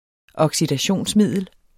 Udtale [ ʌgsidaˈɕoˀns- ]